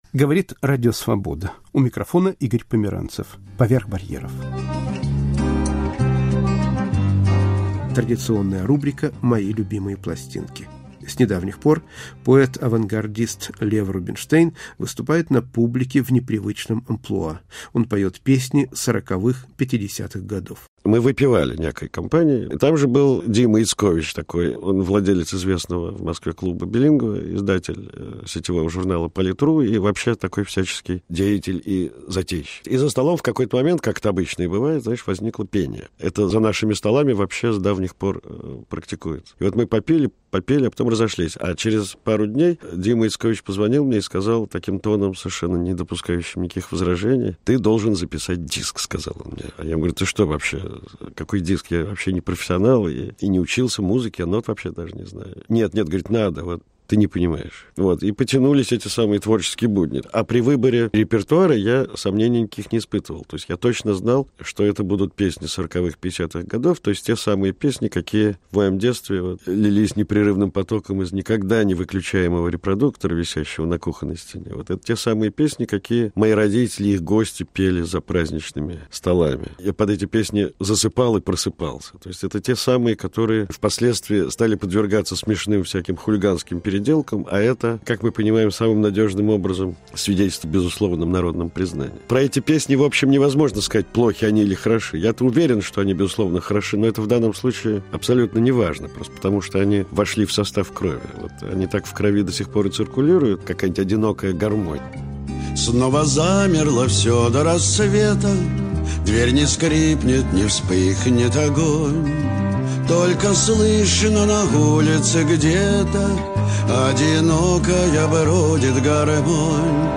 Поет Лев Рубинштейн